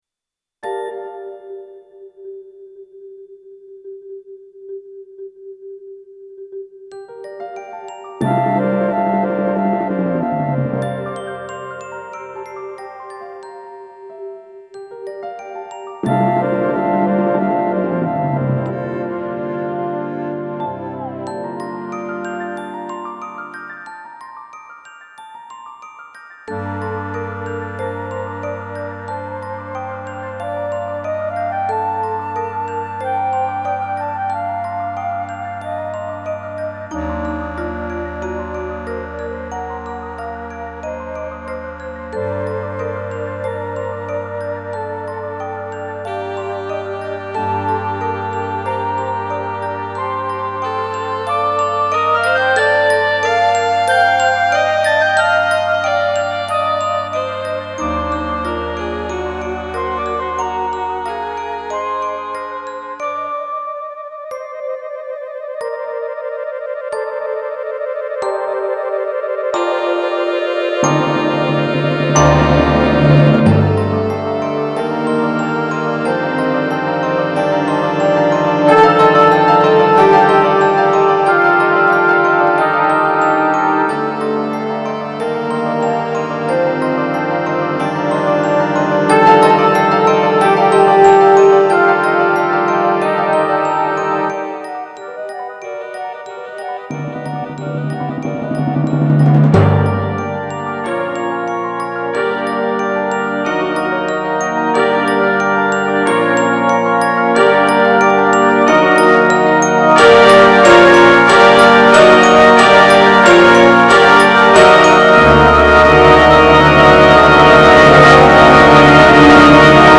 Genre: Band
Piano
Timpani (4), Suspended Cymbal